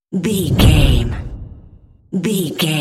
Dramatic hit deep fast trailer
Sound Effects
Atonal
Fast
heavy
intense
dark
aggressive
hits